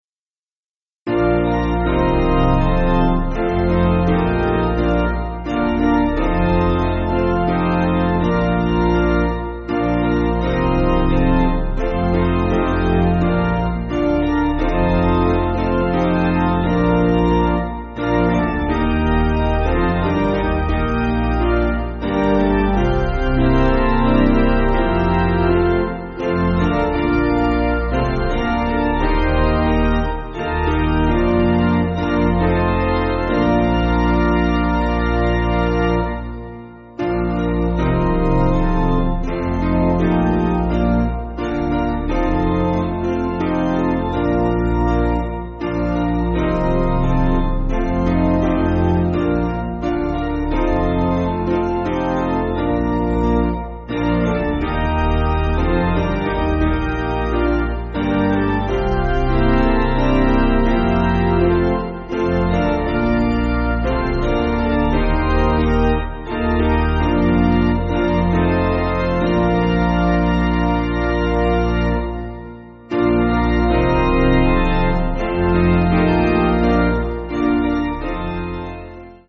Basic Piano & Organ
(CM)   3/Bb